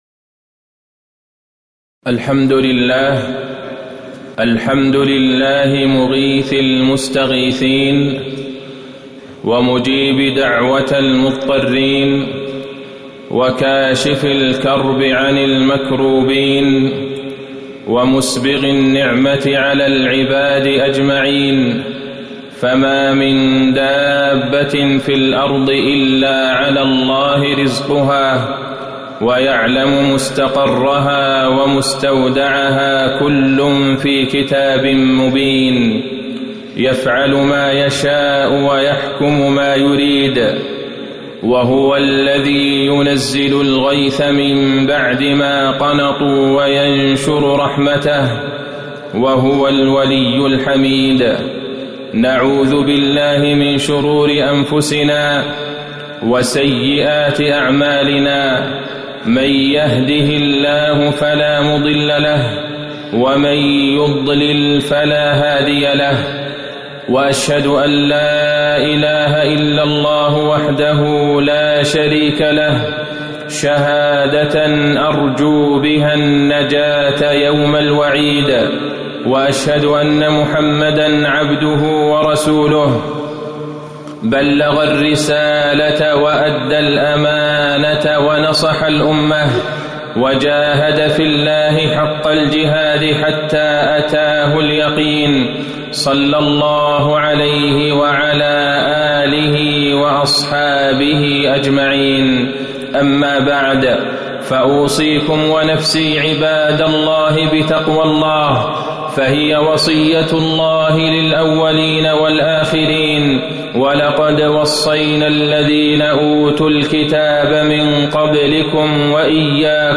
تاريخ النشر ٢١ ربيع الثاني ١٤٤٠ هـ المكان: المسجد النبوي الشيخ: فضيلة الشيخ د. عبدالله بن عبدالرحمن البعيجان فضيلة الشيخ د. عبدالله بن عبدالرحمن البعيجان شكر الله على نزول الغيث